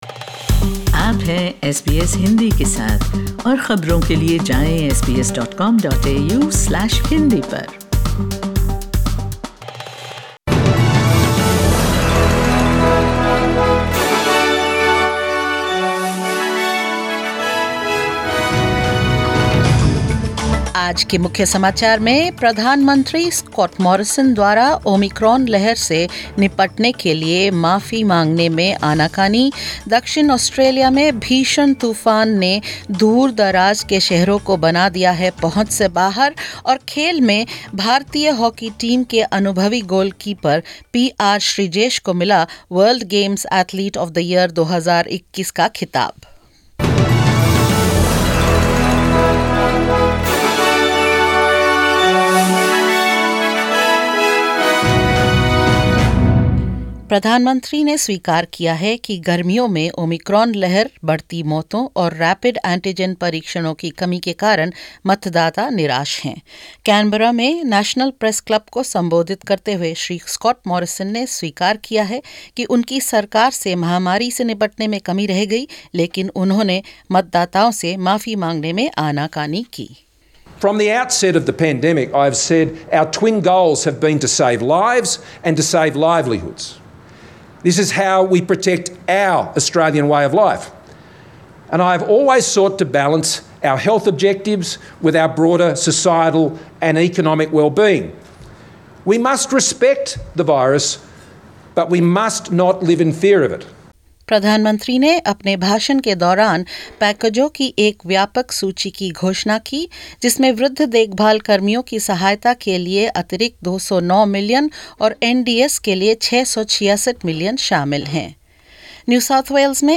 bulletin